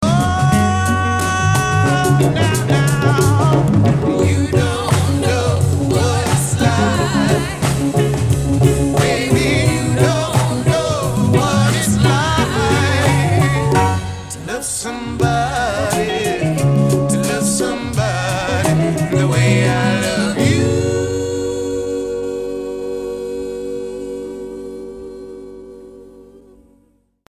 Vocals and piano
Recorded on April 3, 1969 at a TV show in Munich, Germany
The arrangements are rhythmically adventurous.